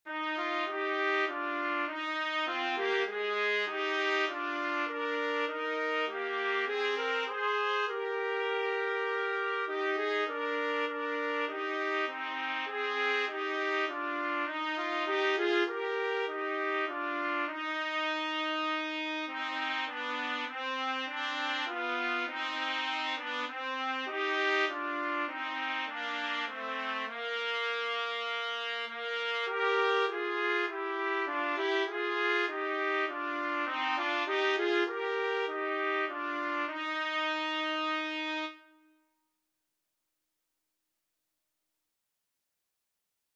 Christmas Christmas Trumpet Duet Sheet Music It Came Upon the Midnight Clear
Free Sheet music for Trumpet Duet
Eb major (Sounding Pitch) F major (Trumpet in Bb) (View more Eb major Music for Trumpet Duet )
4/4 (View more 4/4 Music)
Trumpet Duet  (View more Easy Trumpet Duet Music)
Classical (View more Classical Trumpet Duet Music)